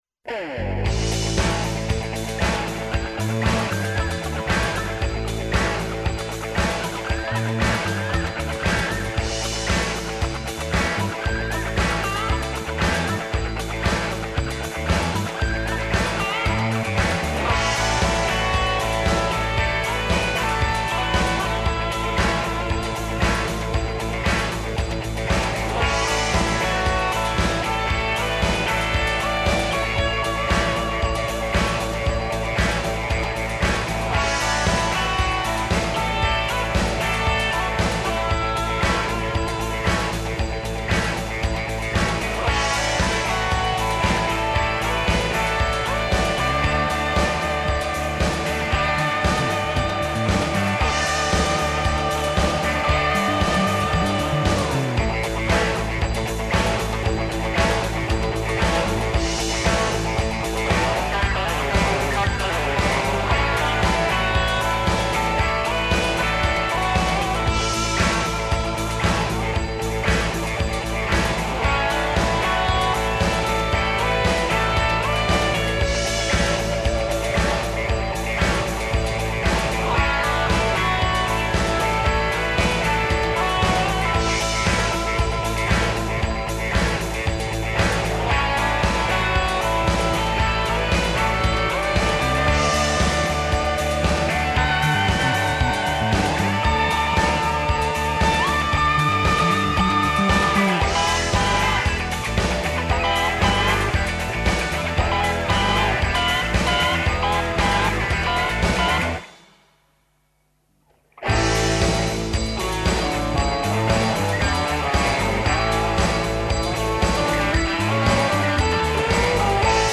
У Магазину говоре организатори и учесници Ноћи истраживача из Београда, Новог Сада, Зрењанина, Суботице, Париза, Рима, Атине, Лондона, као и млади астрономи који нам се јављају с Астрономске олимпијаде из Казахстана.